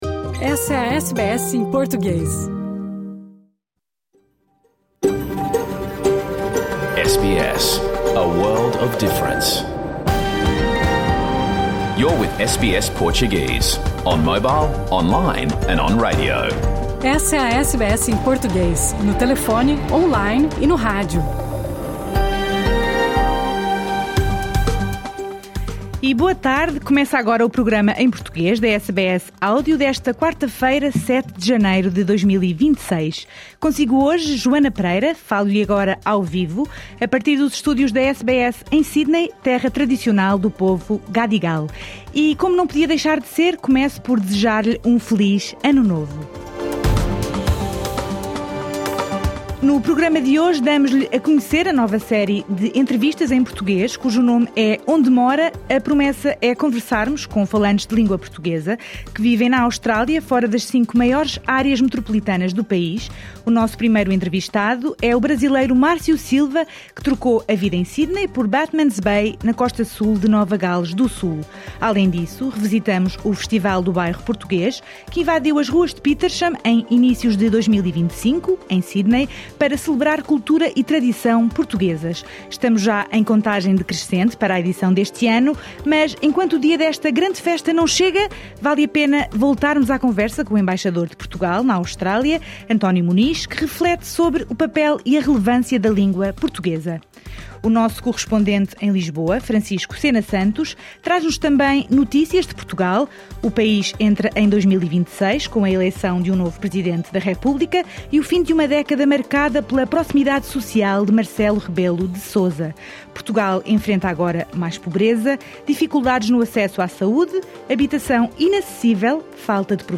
O programa em português com emissão ao vivo pela rádio SBS 2 em toda a Austrália esta quarta-feira. As notícias do dia.